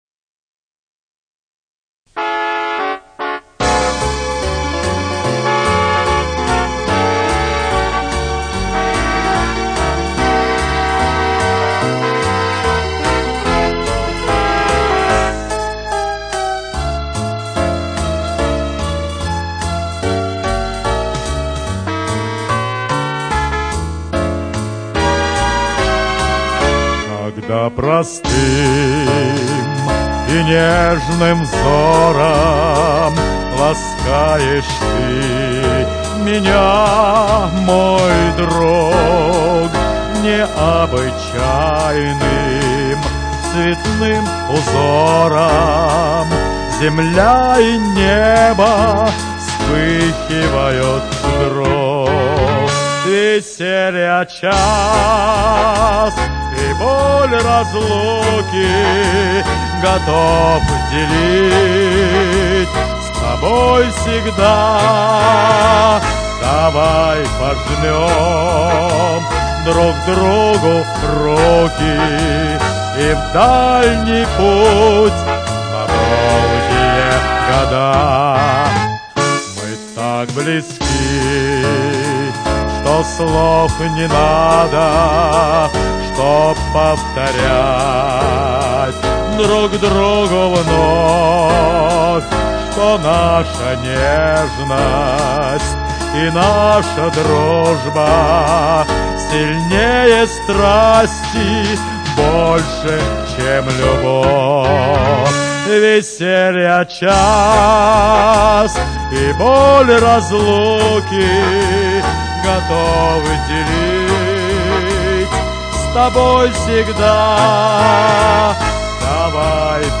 Был в 2003 году свой эстрадный оркестр из 15 человек.
Оркестр
Произведения в исполнении оркестра: